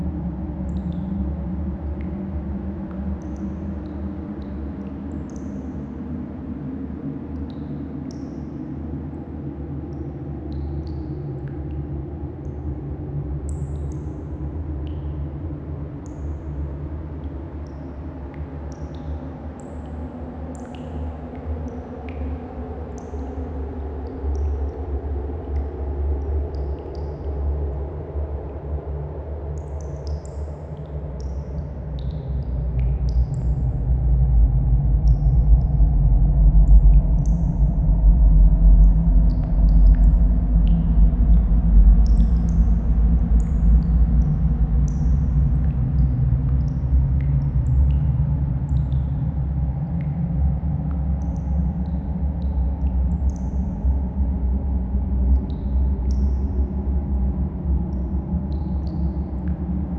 Dungeon_Loop_01.wav